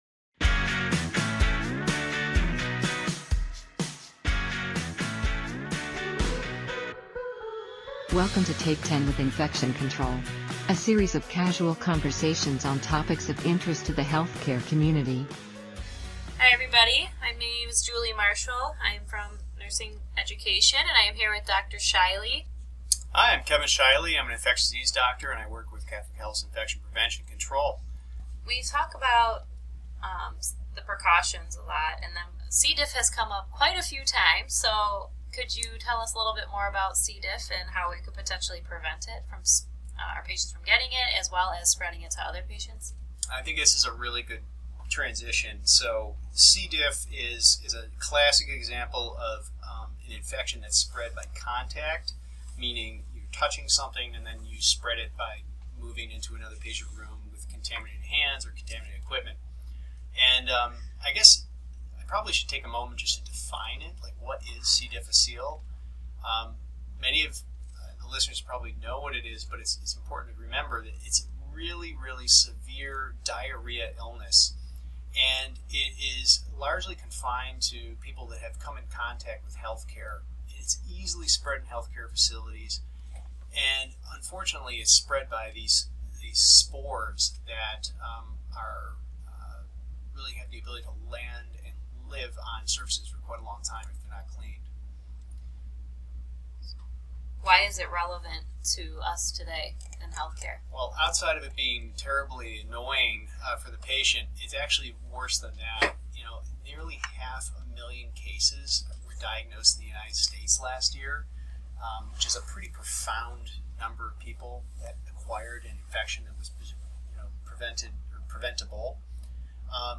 A series of casual conversations